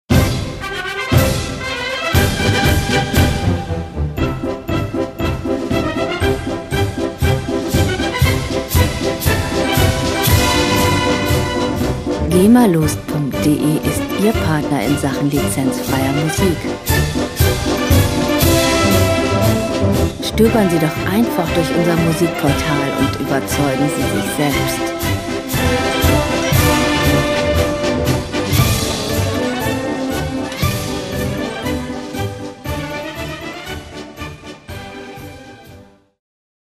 gema-freie Songs aus der Rubrik "Volkslieder"
Musikstil: Marsch
Tempo: 118 bpm
Tonart: C-Dur
Charakter: stramm, zackig